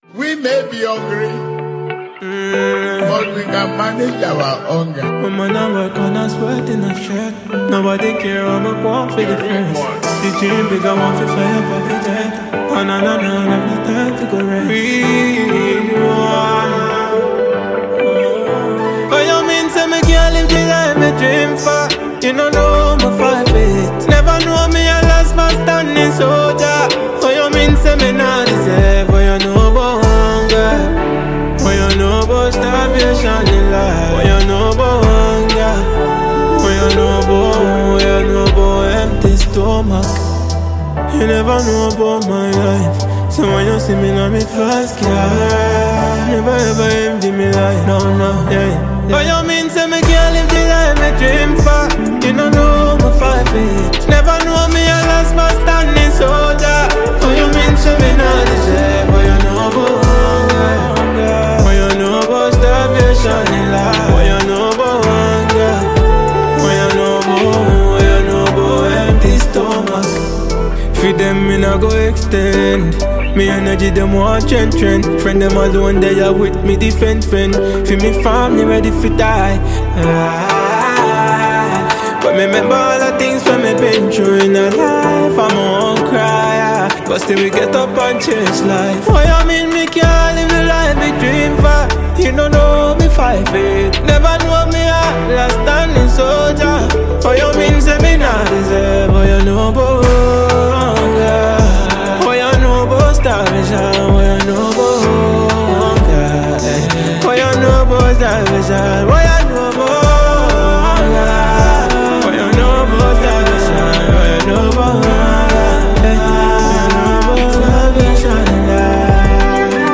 Ghanaian reggae-dancehall musician